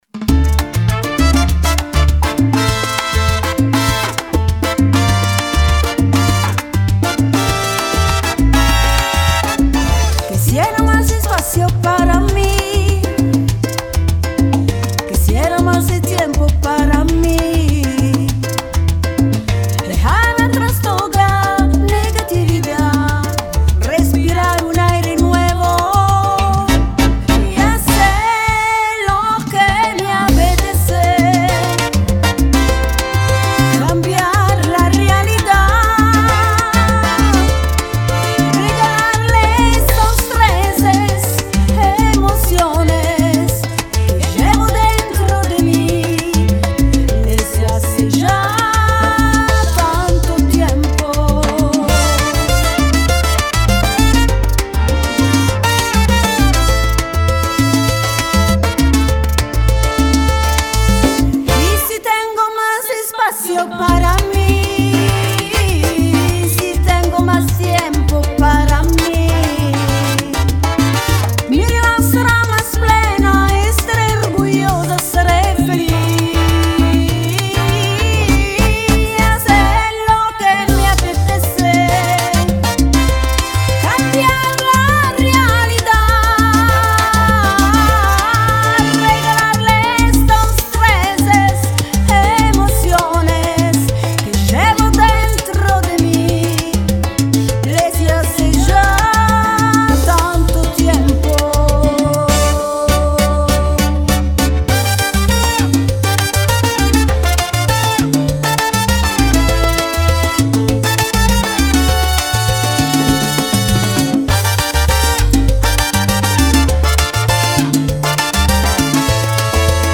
Salsa